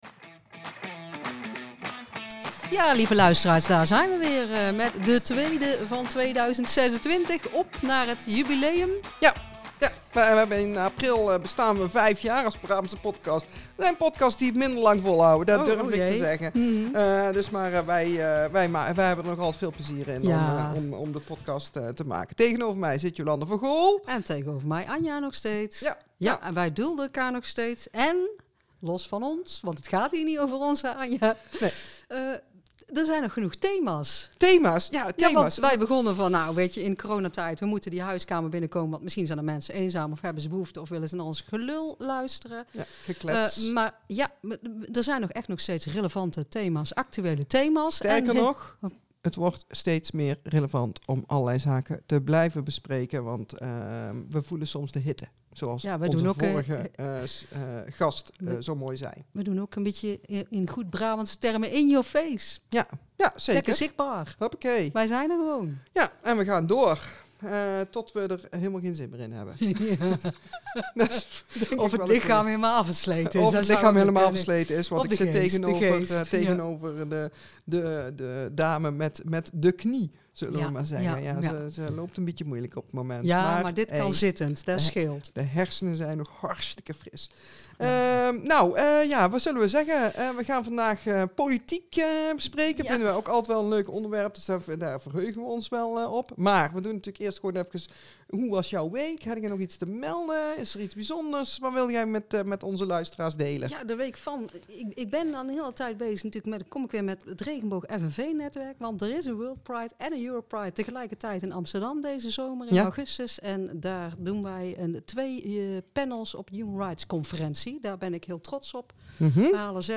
Zij bespreken de lhbti thema's inhoudelijk met daarbij feiten en cijfers, maar ook vanuit eigen ervaring én natuurlijk met een slagje Brabantse humor én gemoedelijkheid.